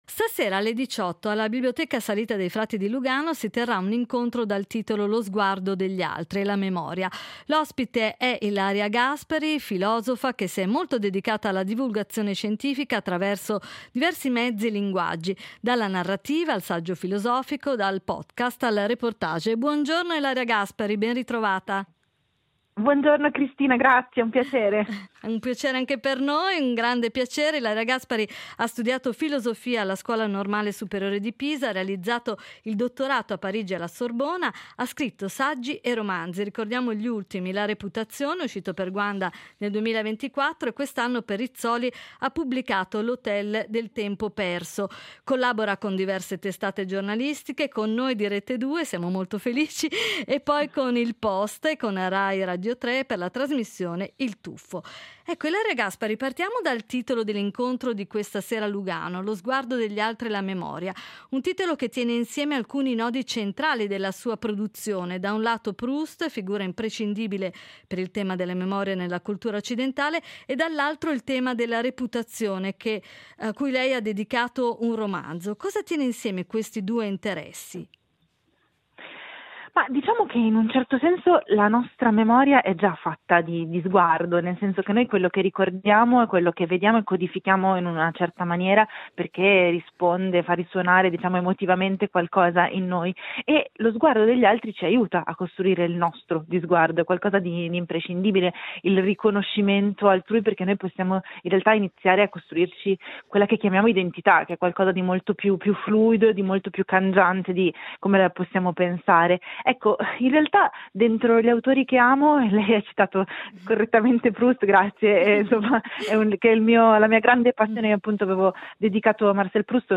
Incontro con Ilaria Gaspari